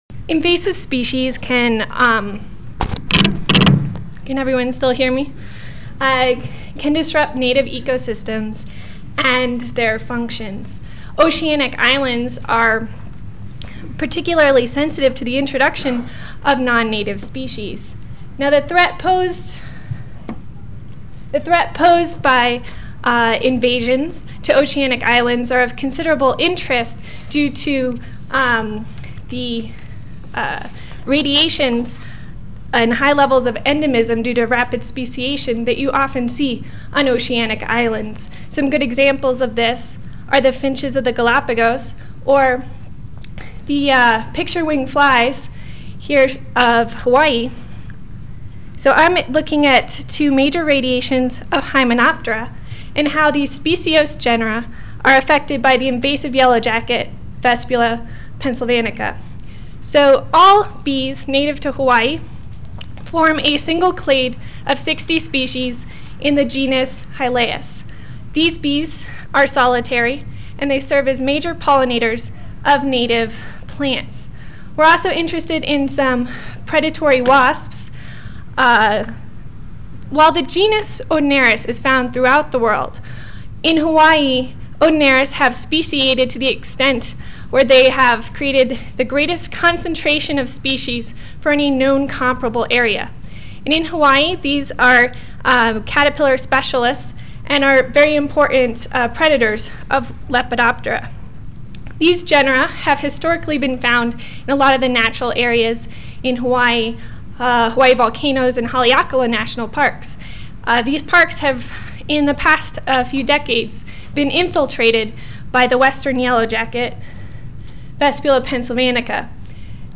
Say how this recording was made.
Room A13, First Floor (Reno-Sparks Convention Center)